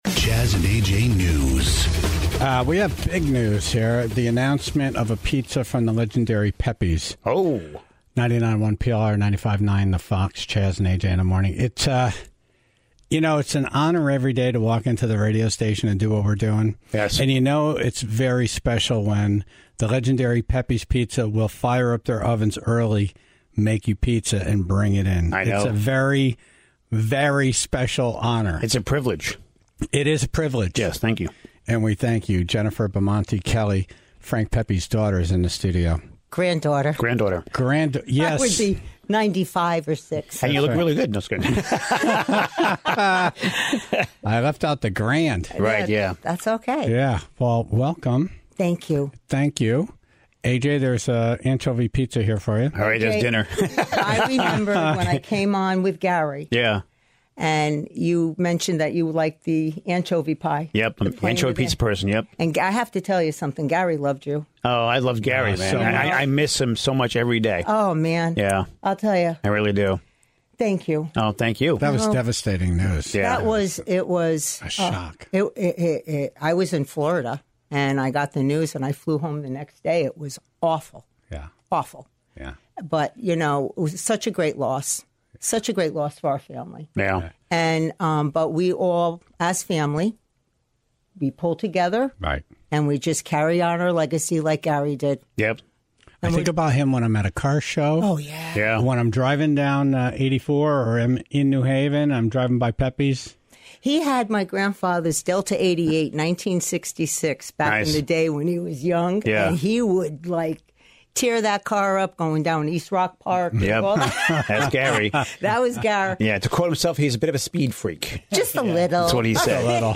in studio this morning